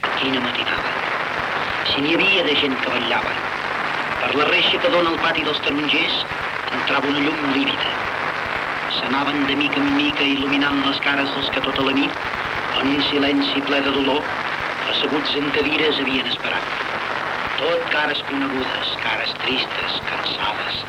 Crònica titulada “La mort del President Macià”.
Informatiu